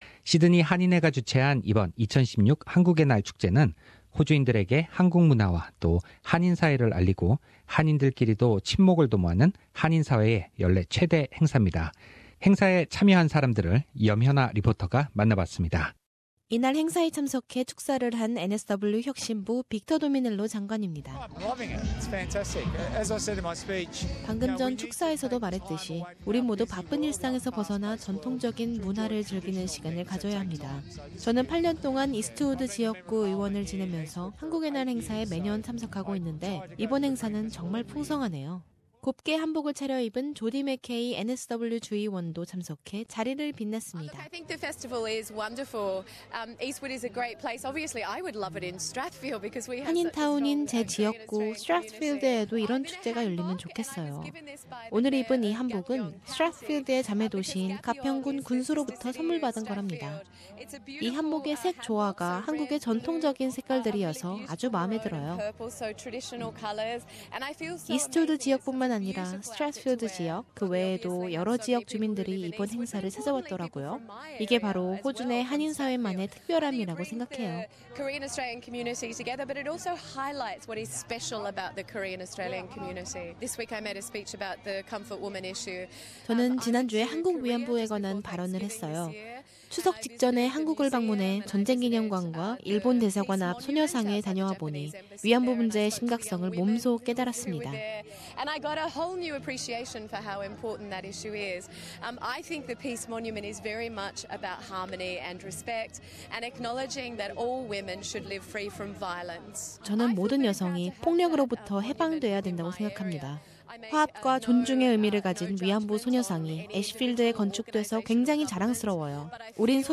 2016 Korean Day Festival at Eastwood Park Source: SBS